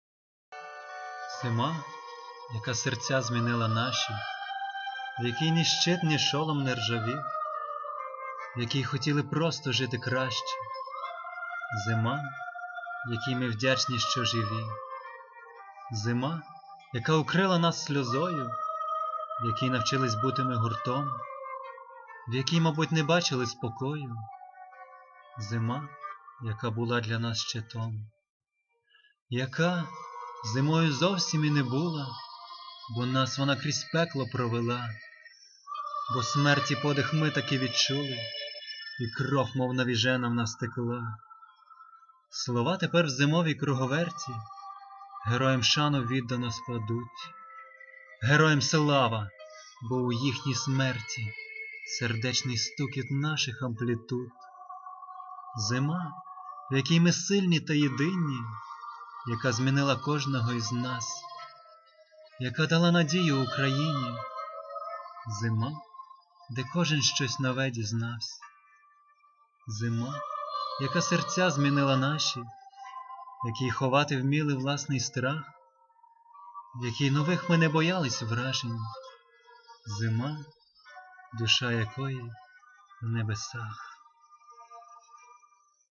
Чуттєве виконання!